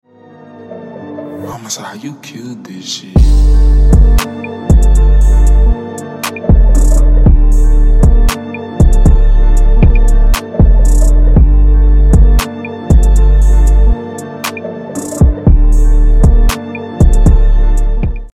#808s